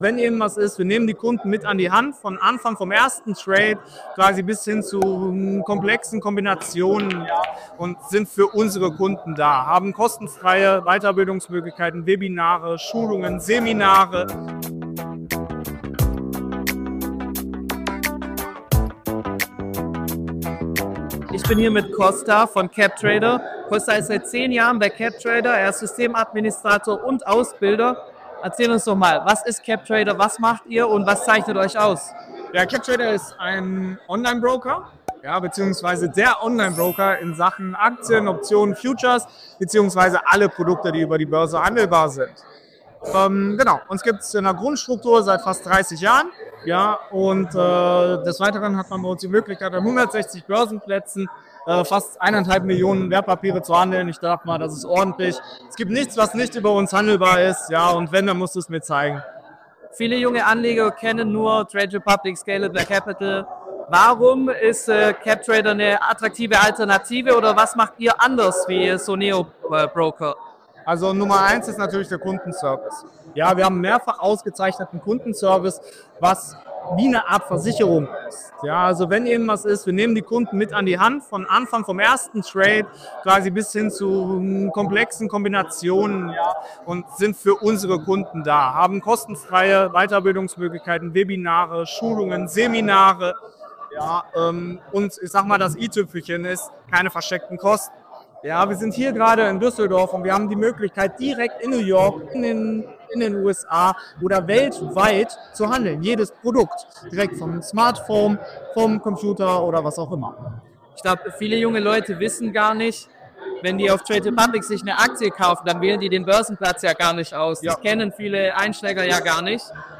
Interview | Anlegertag Düsseldorf 2025 (8/8)